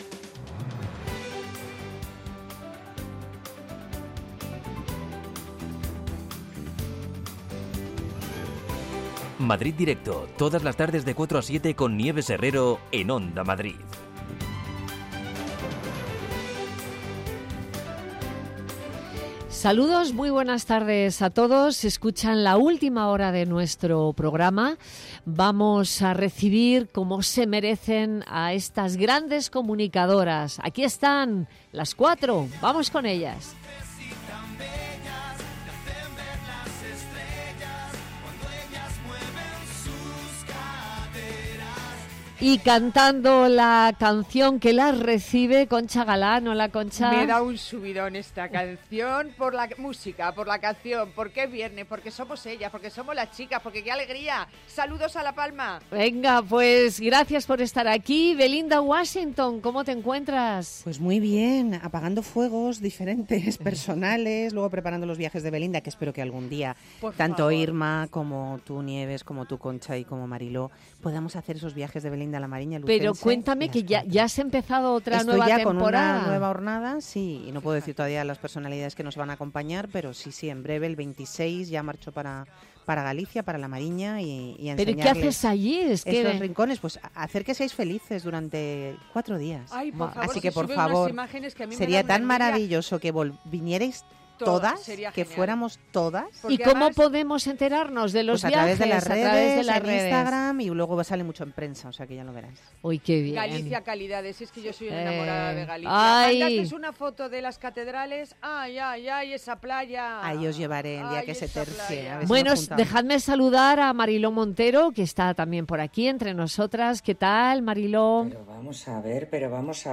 Nieves Herrero se pone al frente de un equipo de periodistas y colaboradores para tomarle el pulso a las tardes.
La primera hora está dedicada al análisis de la actualidad en clave de tertulia.